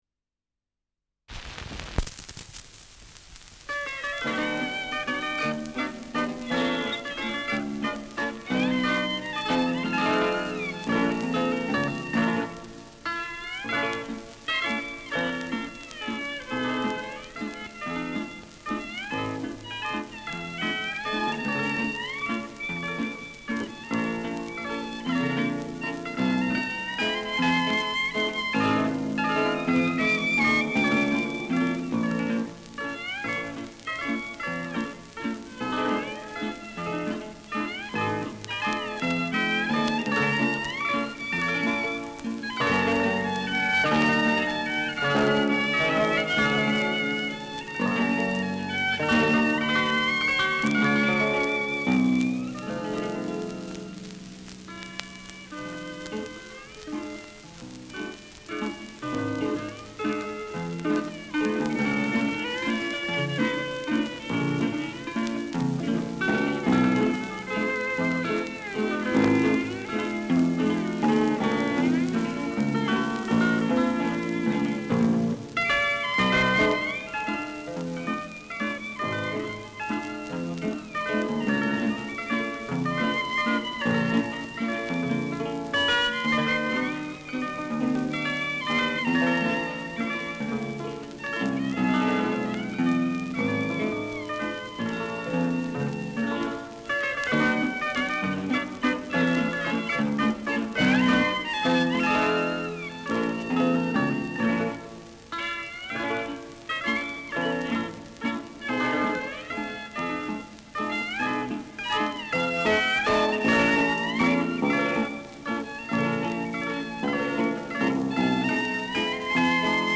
Schellackplatte
Leicht abgespielt : Leichtes Leiern : Gelegentliches Knacken
[München] (Aufnahmeort)